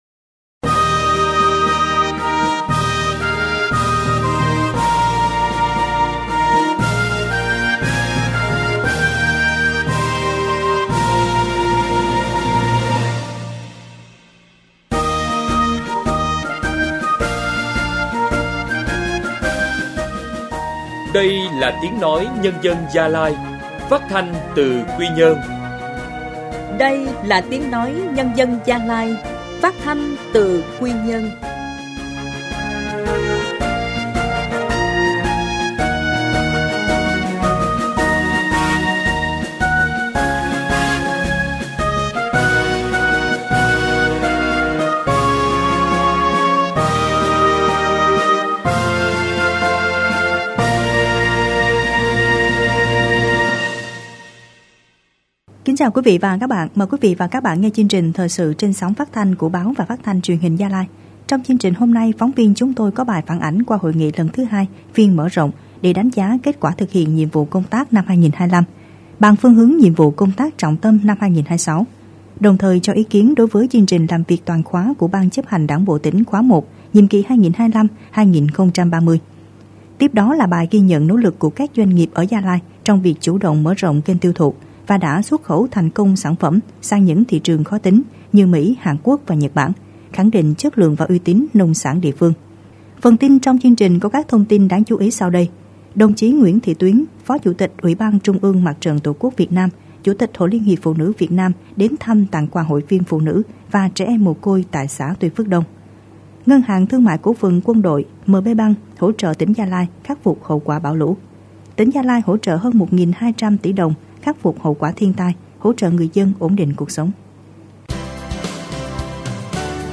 Thời sự phát thanh sáng